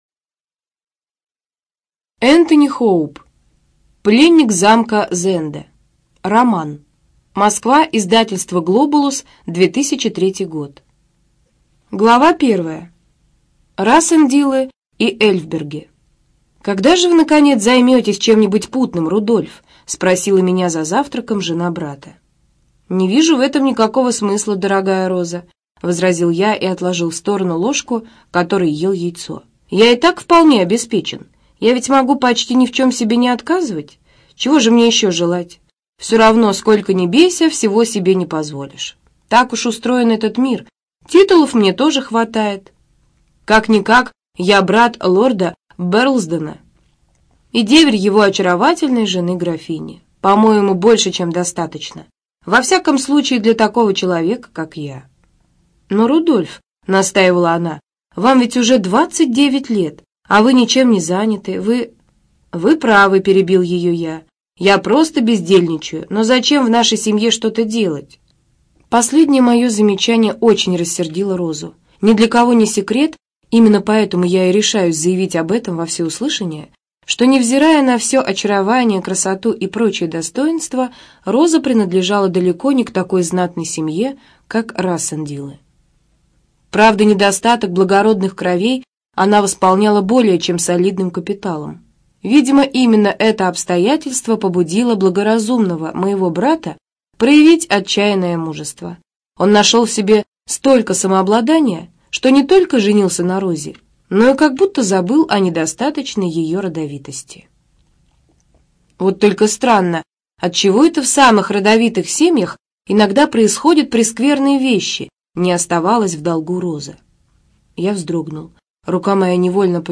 Студия звукозаписиКемеровская областная специальная библиотека для незрячих и слабовидящих